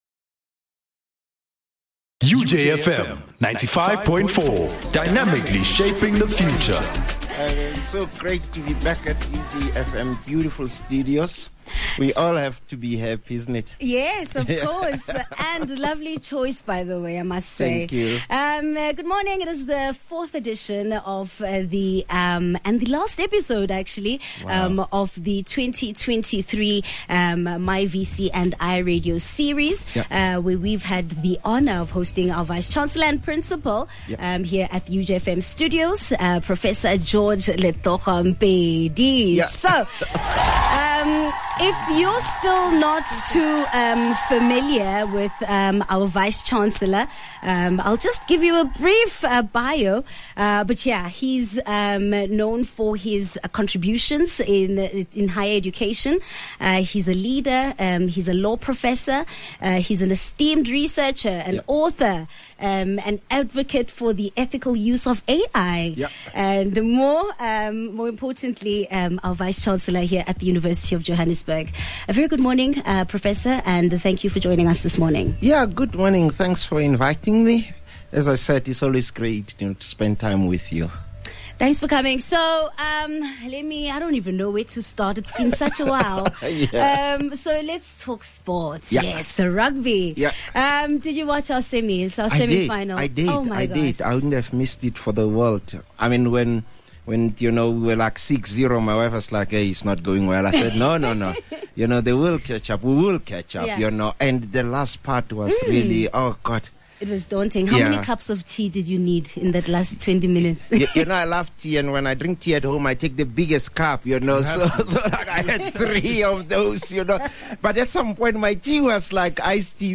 UJFM Radio Series with the Vice-Chancellor and Principal of the University of Johannesburg, Professor Letlhokwa George Mpedi